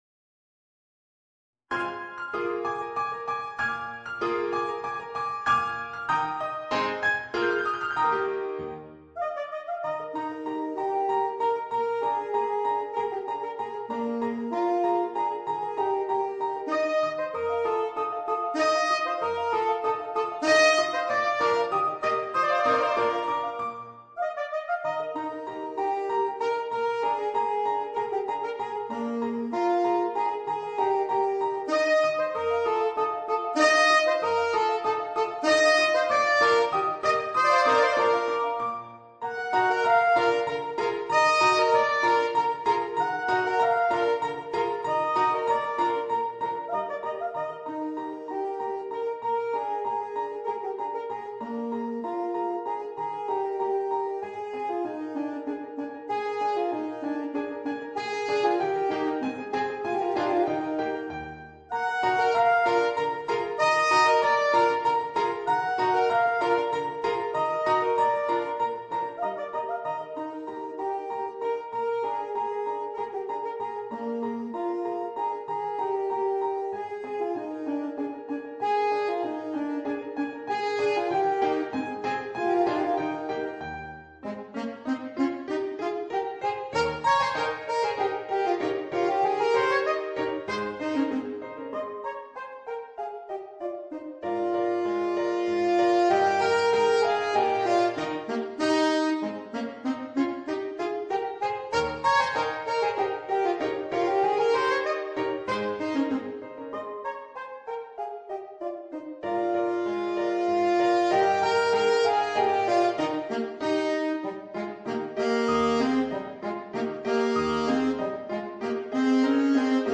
Voicing: Alto Saxophone and Piano